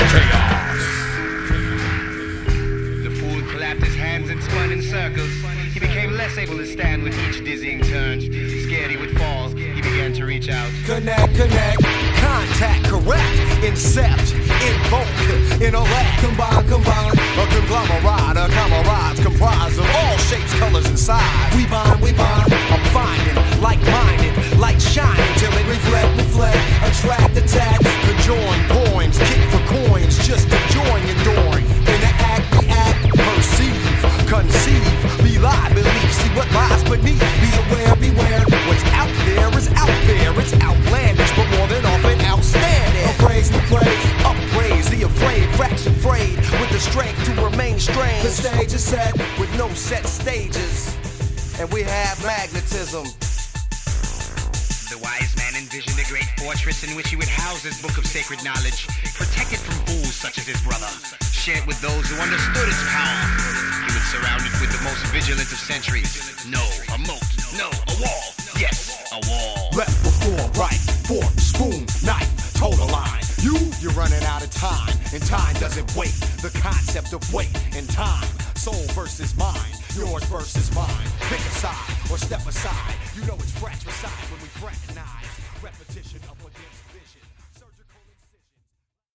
rhymes
beats